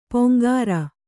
♪ poŋgāra